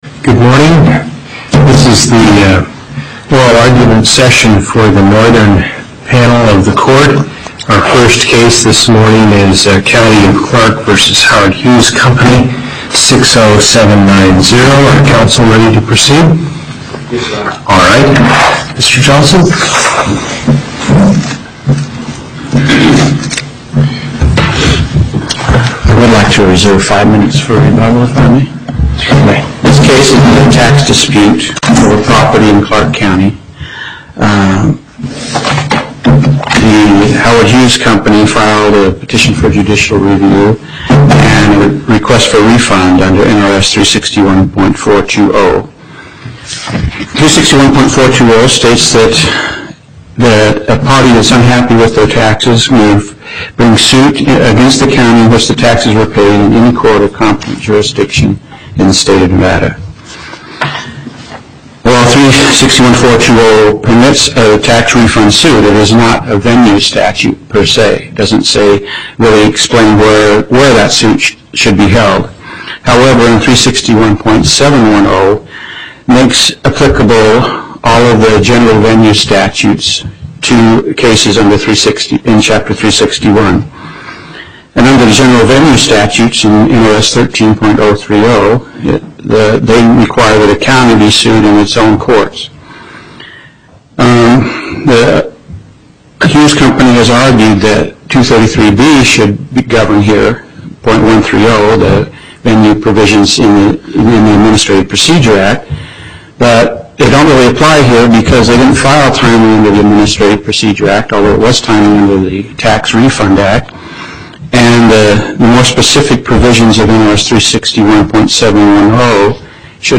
Location: Las Vegas